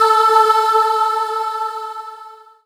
Index of /90_sSampleCDs/Techno_Trance_Essentials/CHOIR
64_04_voicesyn-A.wav